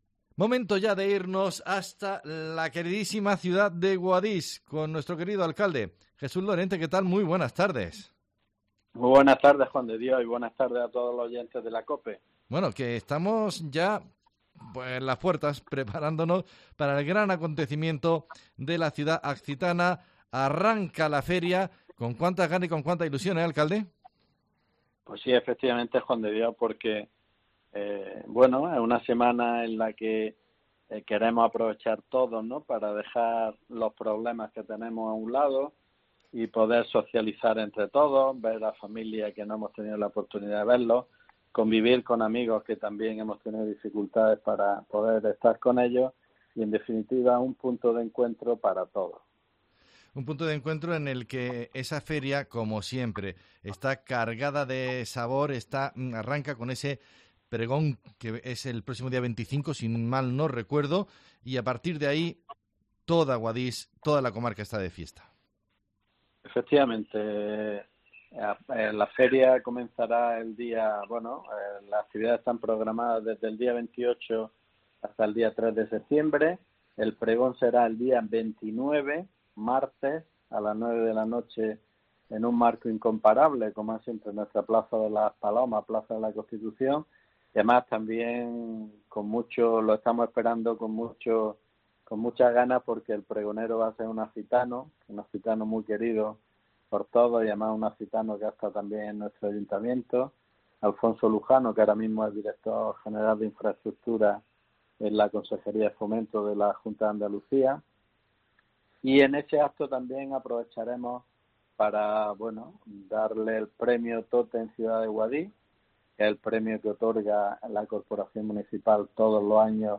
Así vive Guadix su feria. Nos lo cuenta el alcalde Jesús Lorente